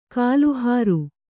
♪ kāluhāru